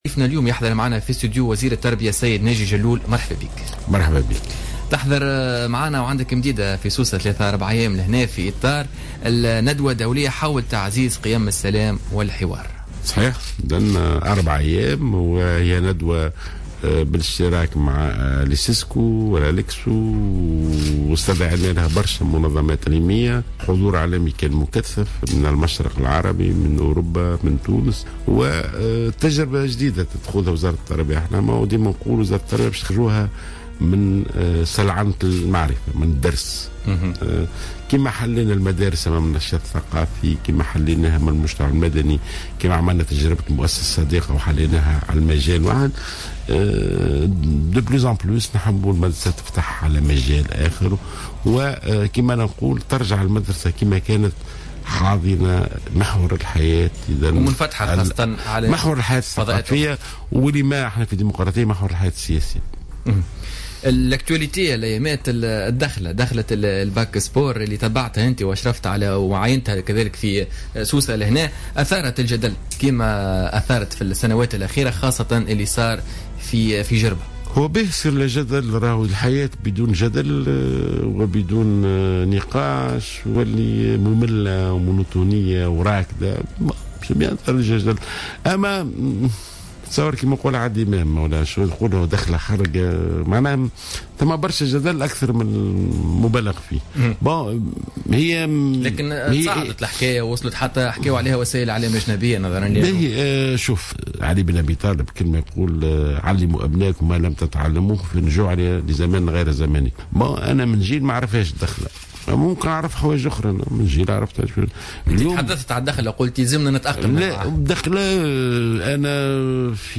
قال وزير التربية ناجي جلول ضيف بوليتيكا اليوم الجمعة 22 أفريل 2016 إن دخلة الباك سبور هي "بدعة" حسنة وجب تأطيرها والتأقلم معها لأنها أصبحت جزء من حياة الشباب .